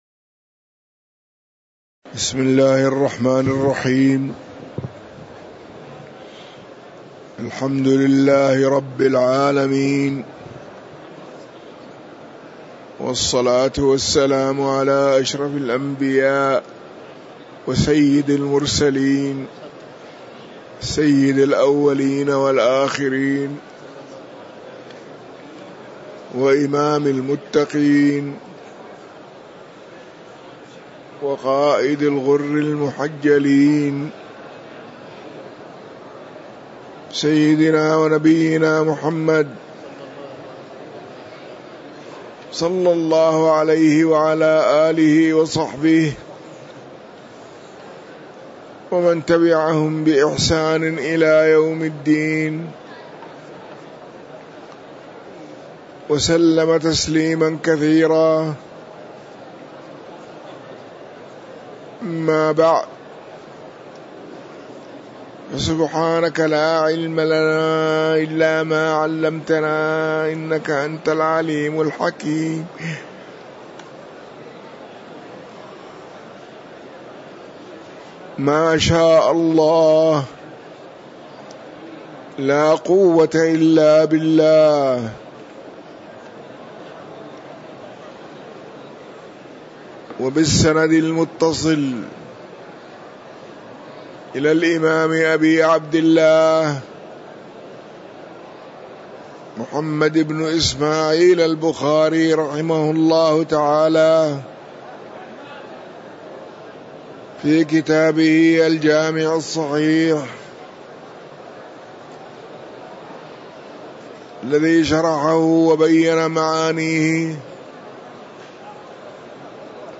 تاريخ النشر ١٣ رمضان ١٤٤٥ هـ المكان: المسجد النبوي الشيخ